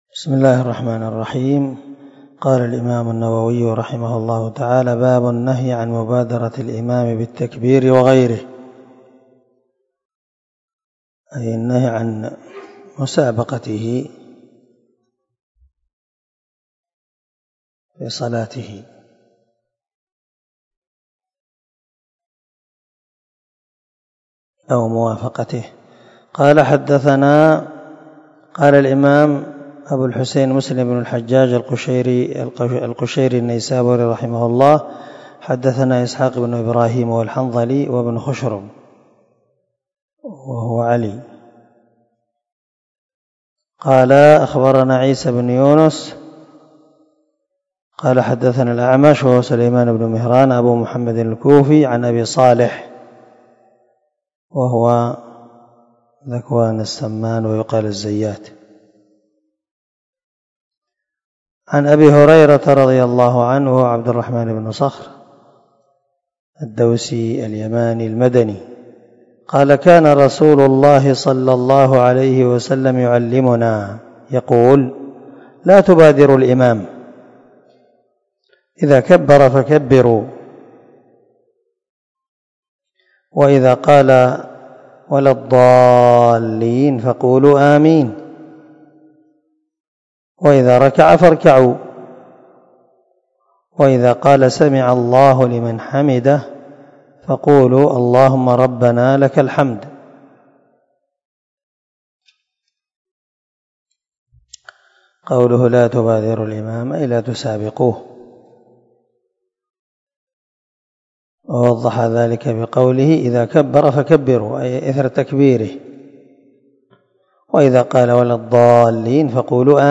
287الدرس 31 من شرح كتاب الصلاة حديث رقم ( 415 – 418 ) من صحيح مسلم